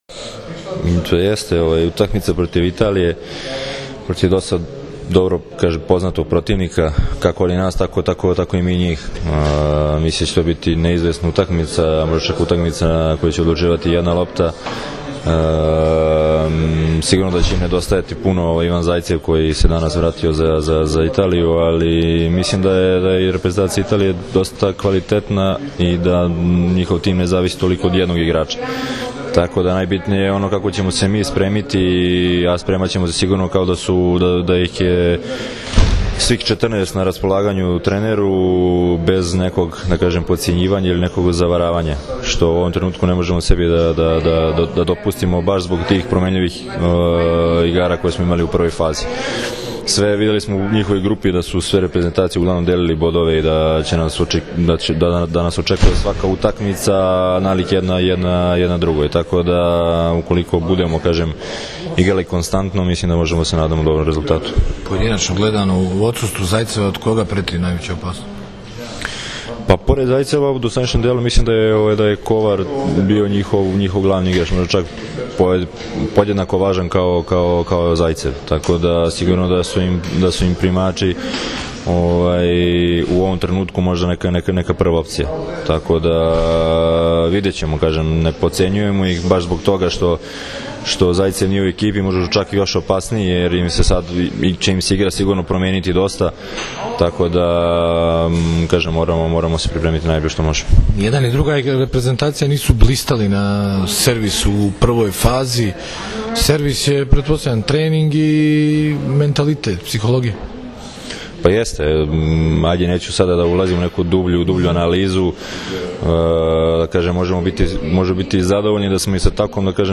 Održana konferencija za novinare u Lođu – u sredu Srbija – Italija (16,40 – RTS 2)
IZJAVA